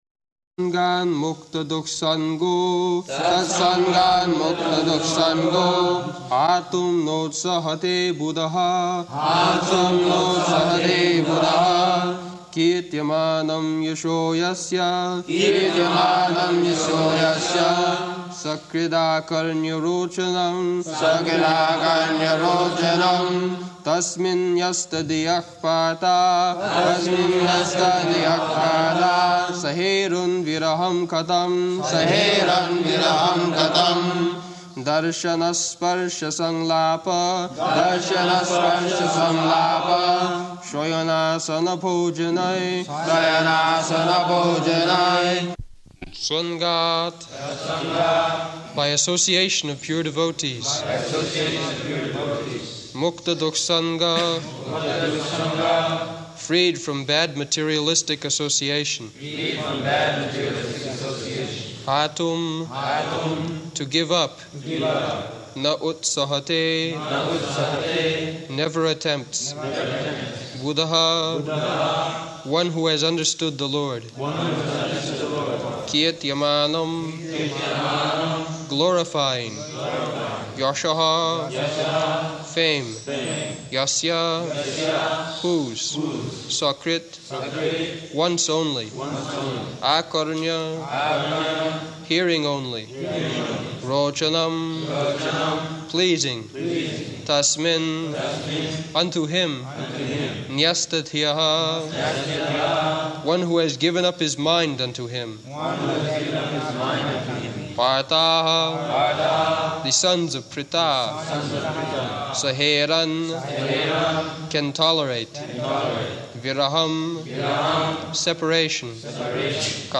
Location: Māyāpur
[Prabhupāda and devotees repeat]